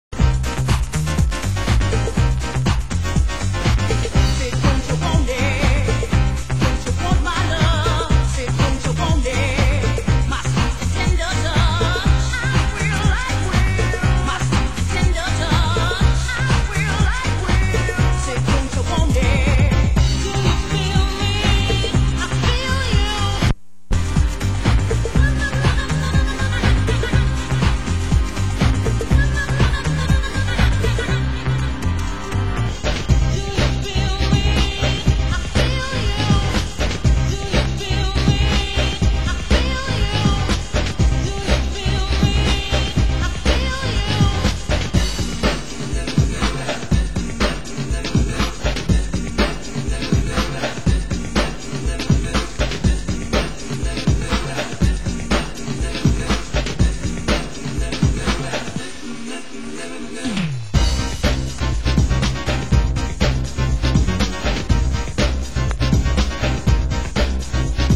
Genre: Progressive